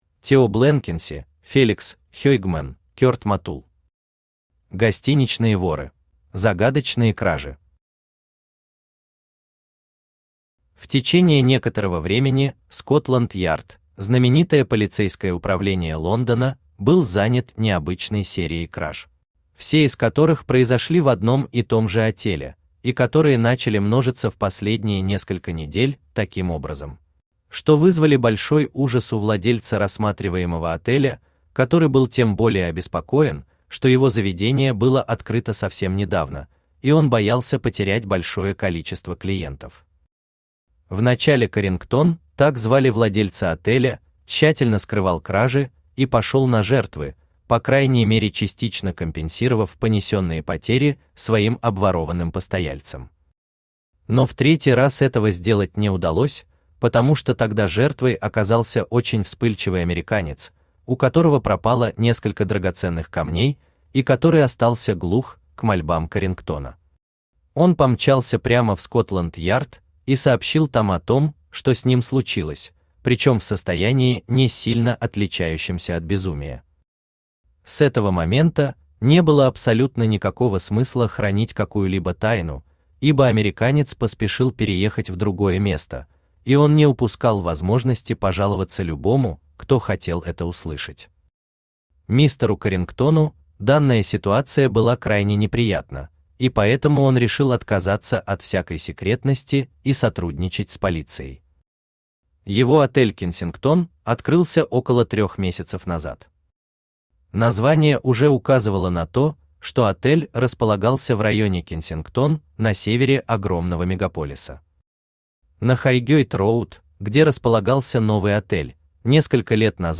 Аудиокнига Гостиничные воры | Библиотека аудиокниг
Aудиокнига Гостиничные воры Автор Тео Блэнкенси Читает аудиокнигу Авточтец ЛитРес.